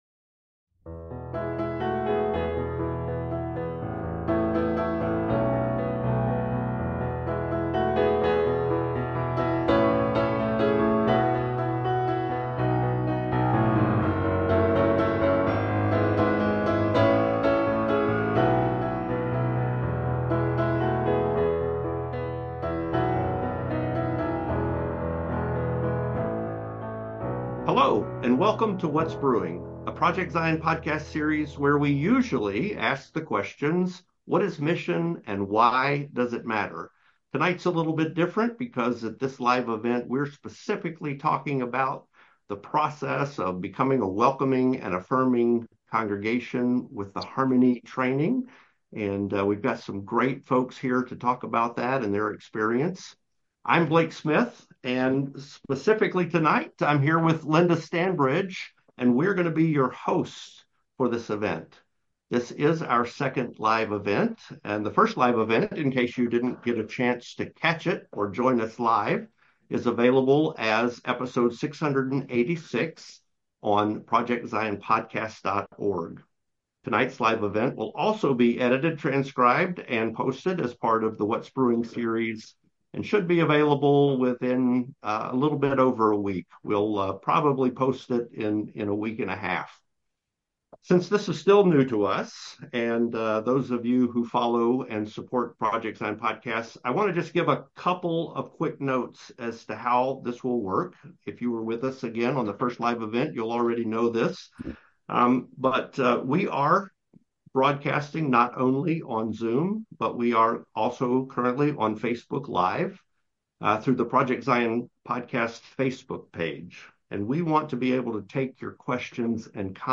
709-whats-brewing-live-event-the-road-to-welcoming-and-affirming.mp3